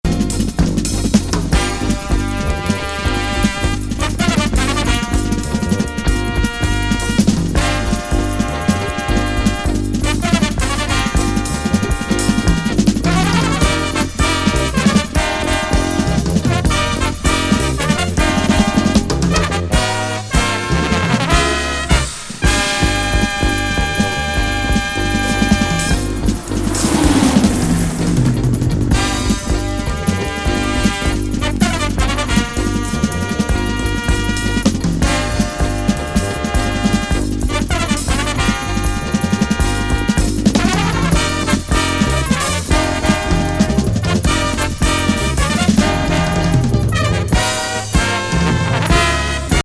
スーパーカーのエンジン音等のバックに、
の和ファンクが高揚感を煽る!帯付。